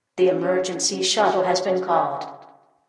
Shuttle called/recalled announcements and sounds.